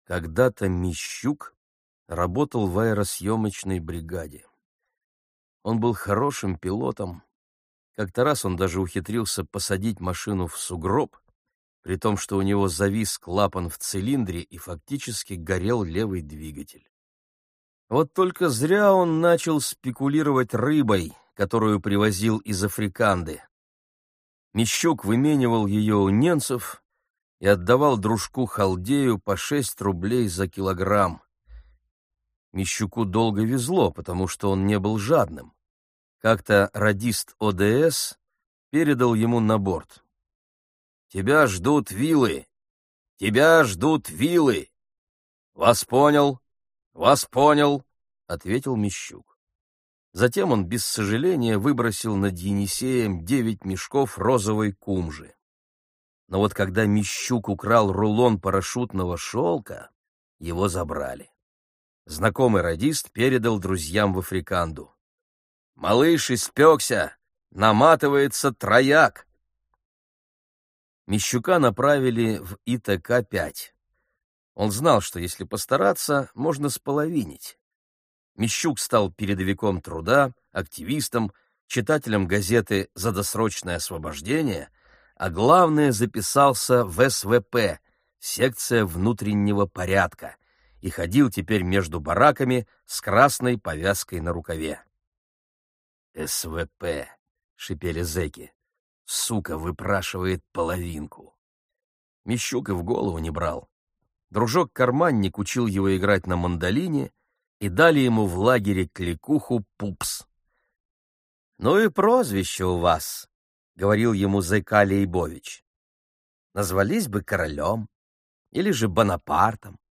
Аудиокнига Зона. Записки надзирателя | Библиотека аудиокниг